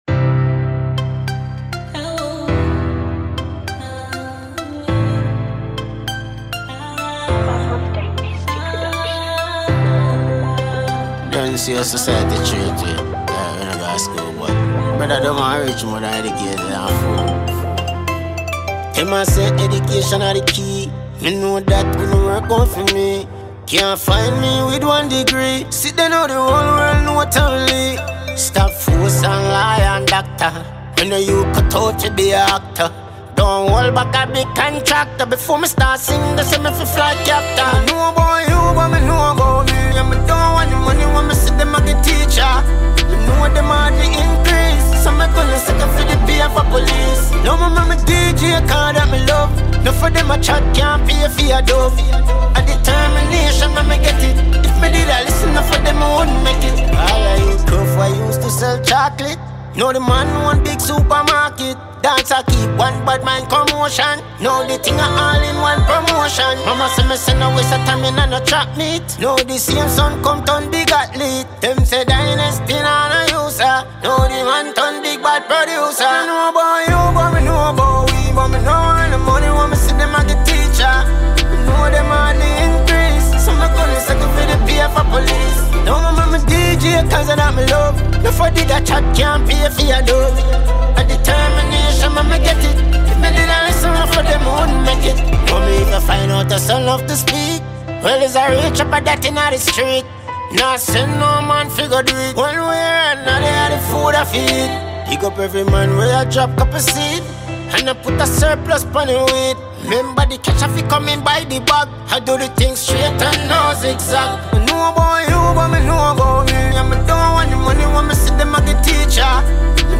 • Genre: Dancehall